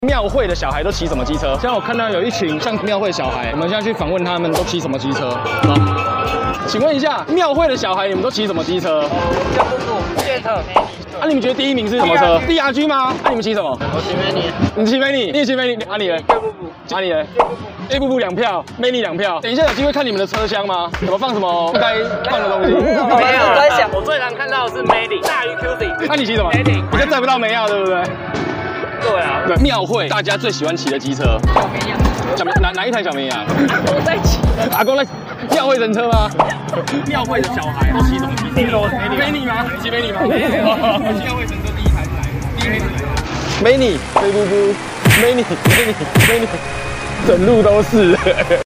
戰地記者來大甲媽訪問，你的車有在裡面嗎？